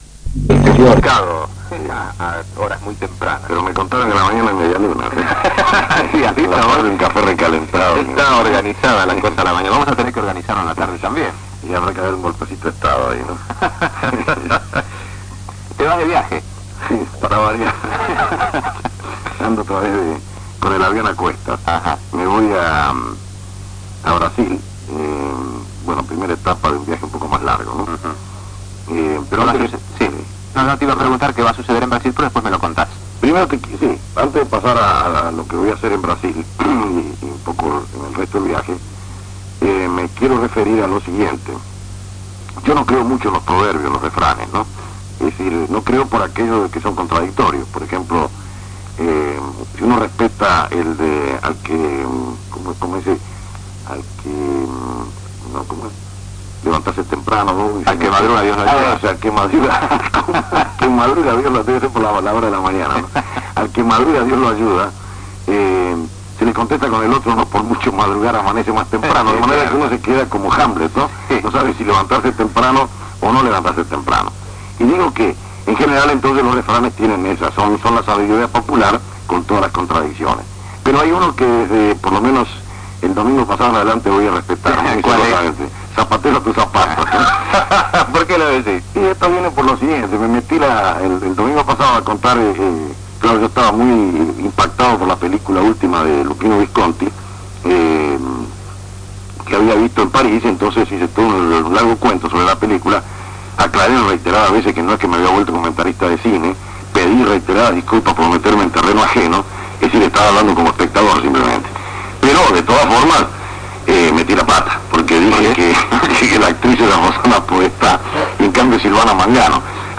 El 4 de Mayo de 1975 Miguel Brascó entrevista a Jorge A. Sabato en su programa "Las 12 horas de la radio" trasmitido por Radio Continental. Programa de Ciencia y Técnica de Brasil, Plan Ciencia y tecnología.